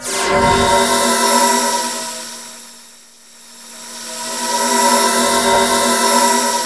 Index of /90_sSampleCDs/E-MU Producer Series Vol. 3 – Hollywood Sound Effects/Science Fiction/Brainstem